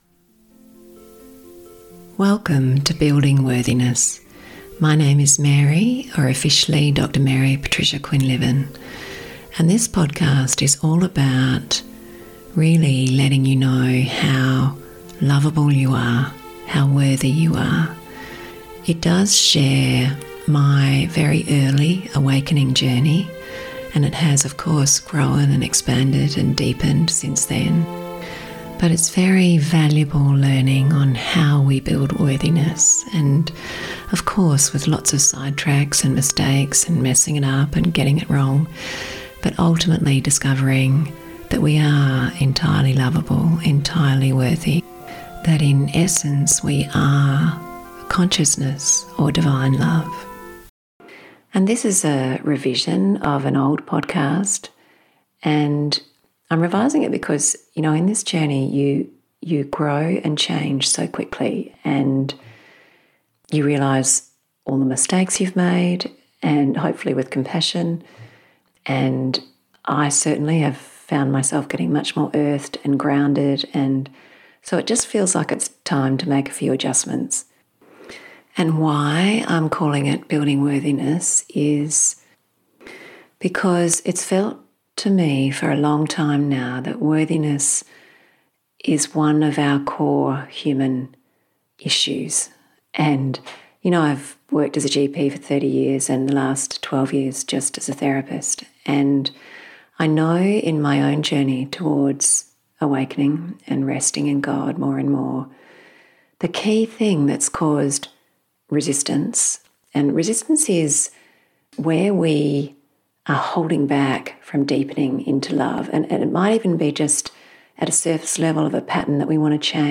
A doctors journey of awakening, a shared journey to worthiness. A warm and gentle podcast on building worthiness towards spiritual awakening.